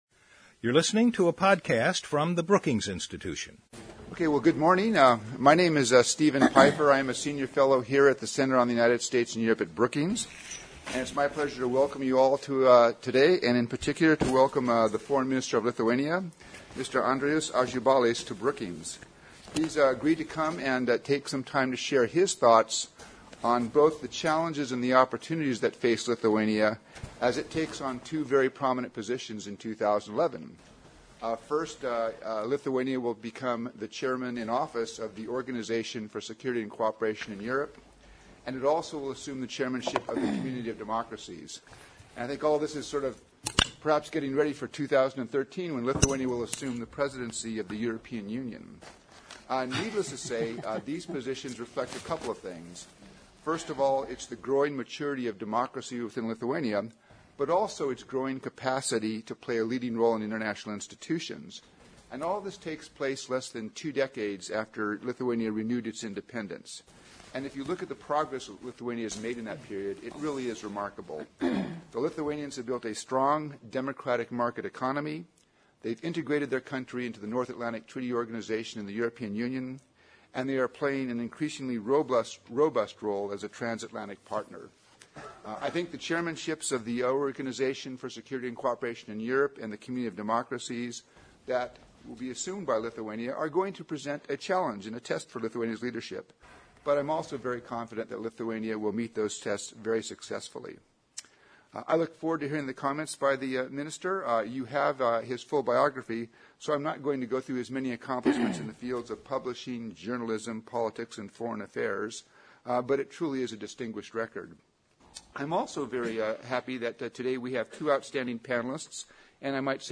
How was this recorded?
After the program, the speakers took audience questions.